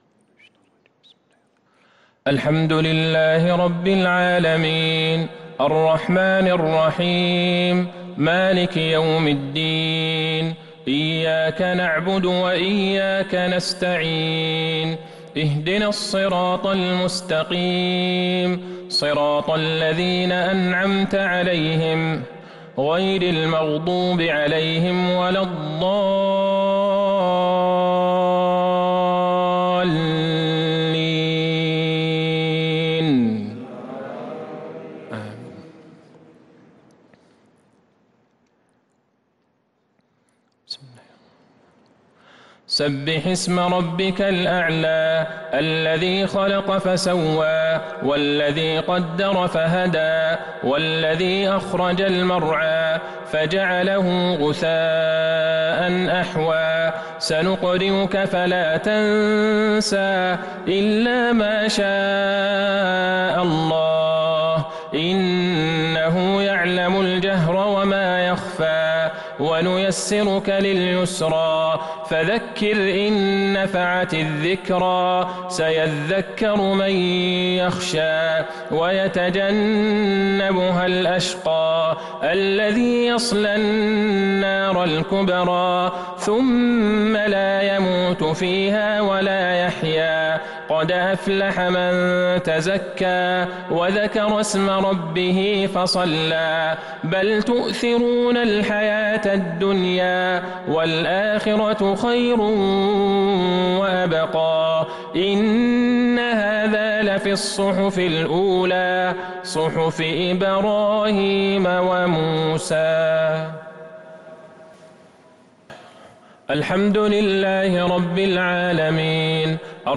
صلاة المغرب للقارئ عبدالله البعيجان 13 ربيع الأول 1443 هـ